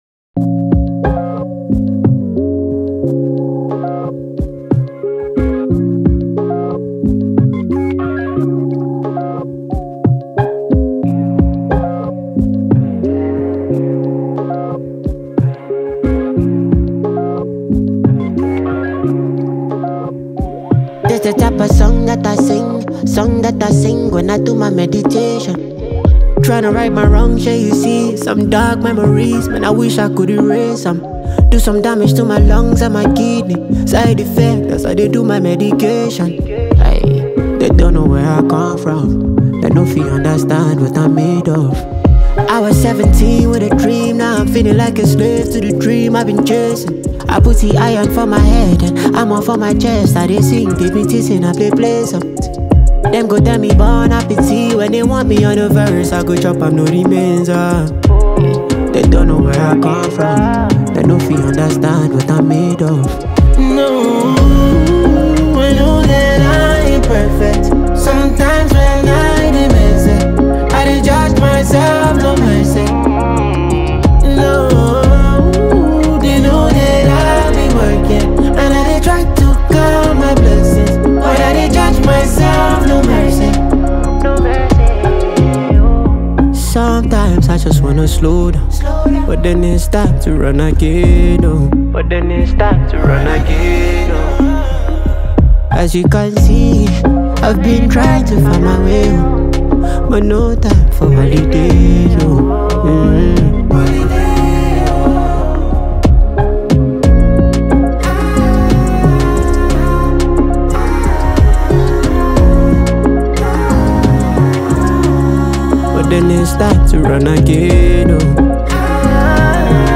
From smooth melodies to hard-hitting rhythms